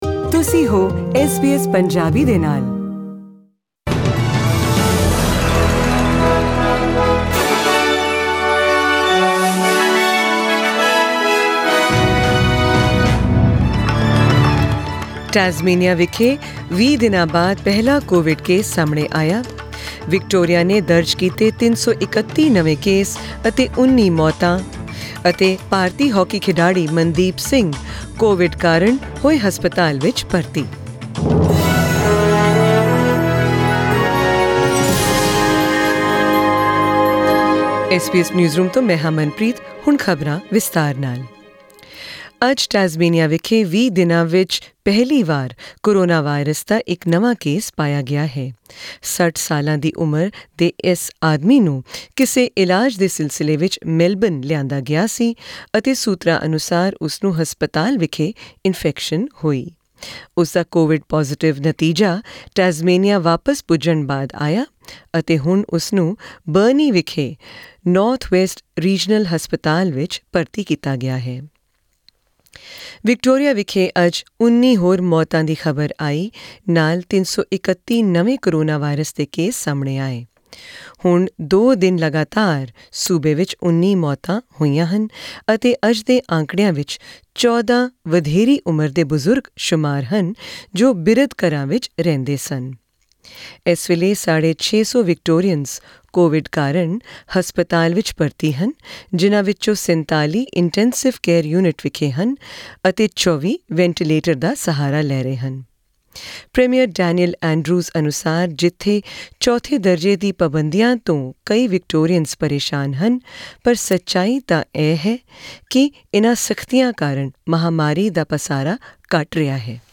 In tonight's SBS Punjabi news bulletin, hear the latest COVID updates from Australia and beyond, and also about Indian hockey team forward Mandeep Singh being admitted in hospital after he tested positive for coronavirus.